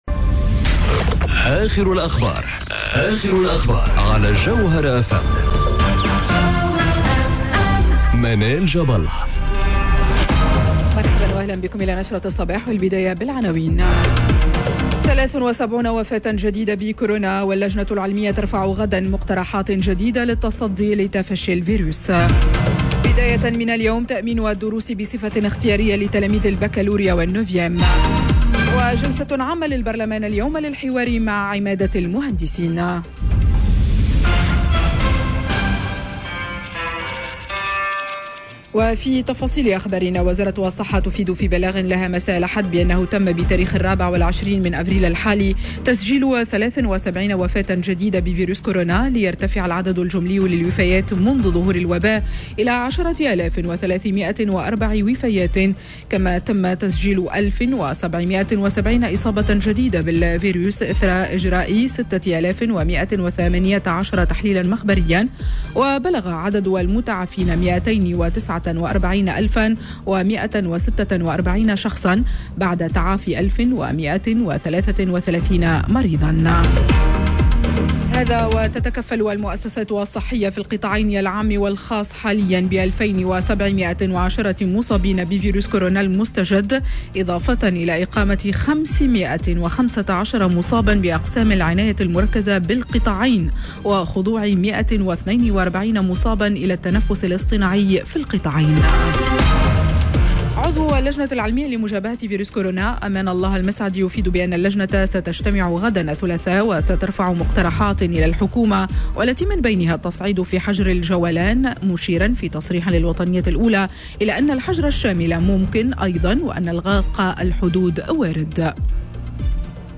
نشرة أخبار السابعة صباحا ليوم الإثنين 26 أفريل 2021